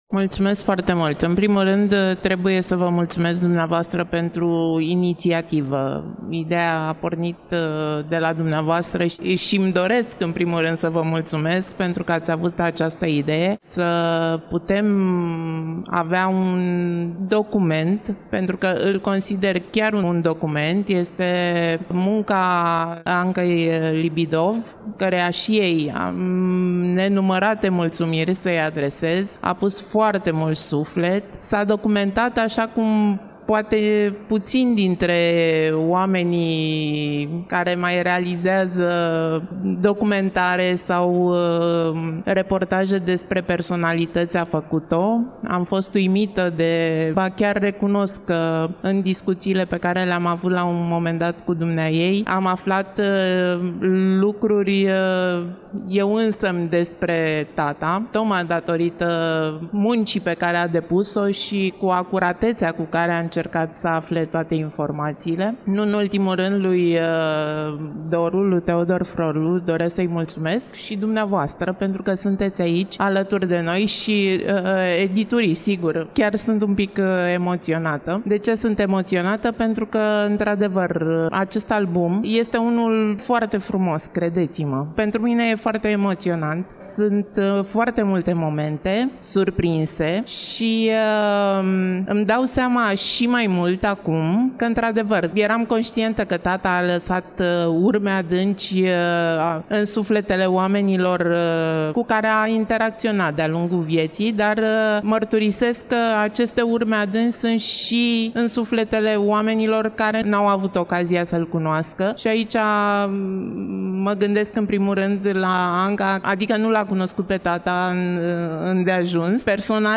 Vocile amintite le-am înregistrat la una din edițiile trecute a Târgului de Carte Gaudeamus Radio România, București, în programul căruia a fost prezentat albumului − Un an fără IVAN.